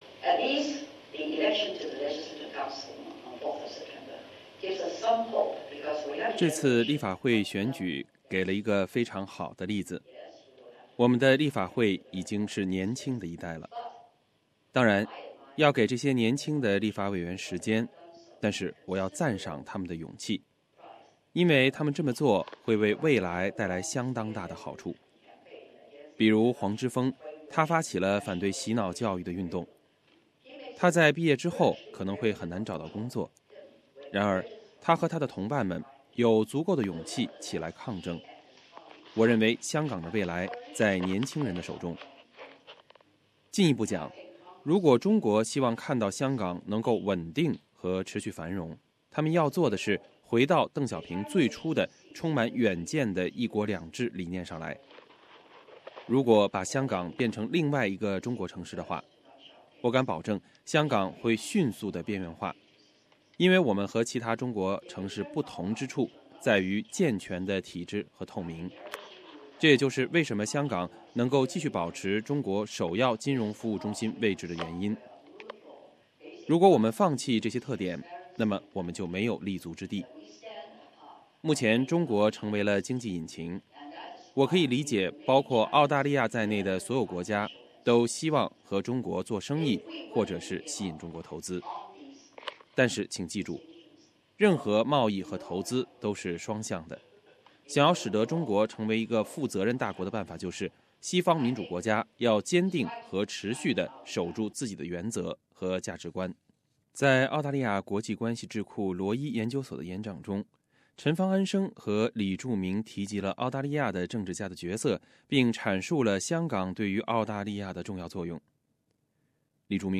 两人就香港本届立法会选举情况，香港独立问题和香港对于澳大利亚的重要作用都做出了回应。请听SBS记者发自现场的录音报道。